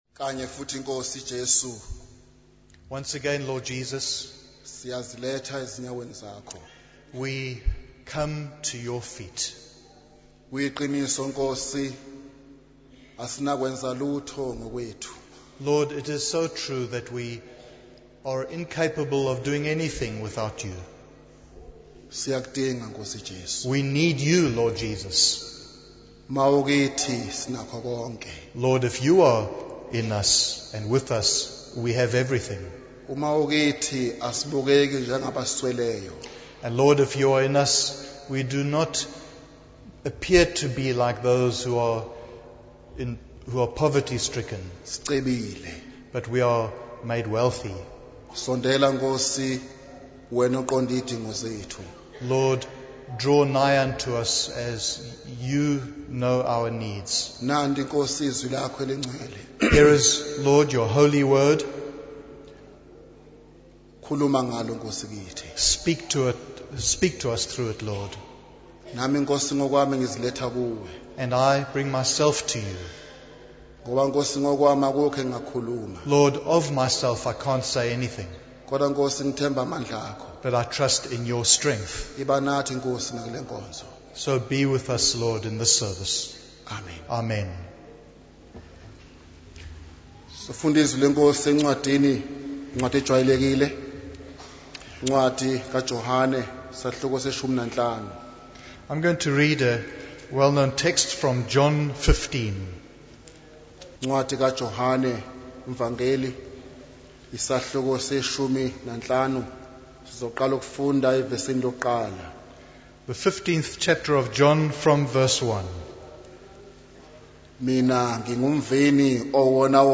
In this sermon, the preacher focuses on the importance of love and obedience in the life of a believer. He emphasizes that Jesus considers his followers not just as servants, but as intimate friends.